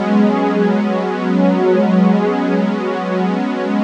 cch_synth_padded_125_Gb.wav